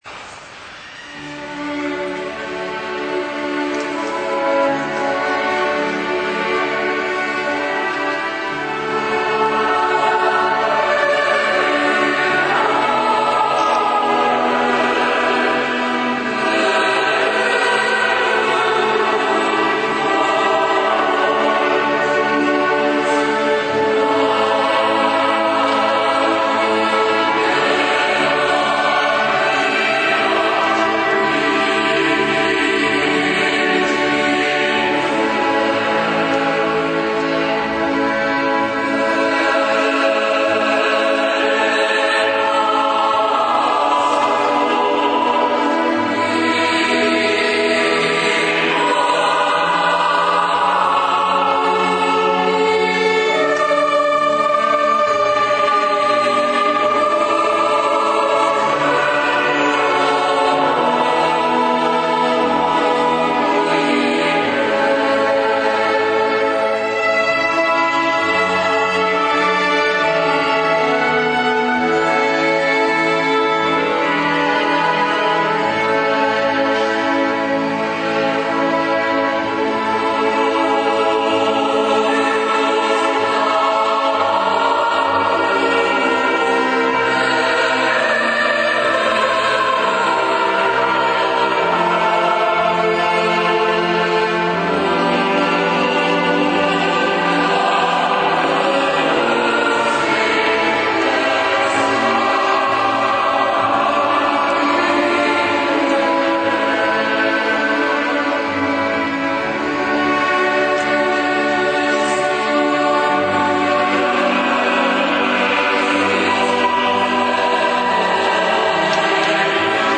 Multimedia : Audio/Audio2013/Concerto4agosto2013/04-AveVerumMozart - Duomo di Monreale